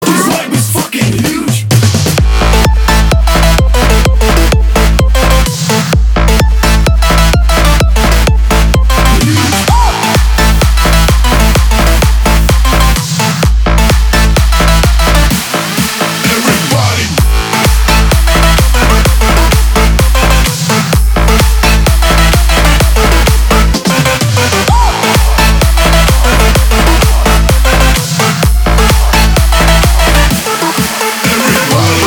• Качество: 320, Stereo
ритмичные
мощные
качающие
Bass
electro